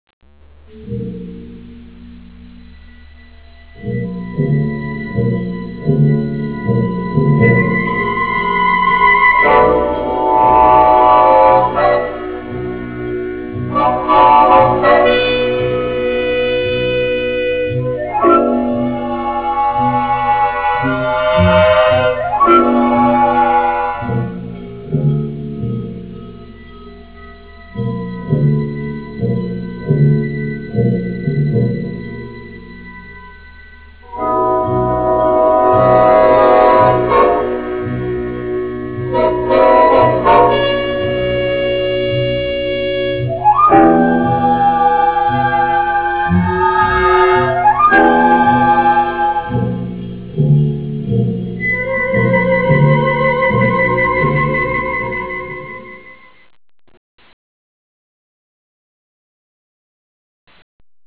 Track Music